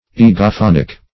Egophonic \E`go*phon"ic\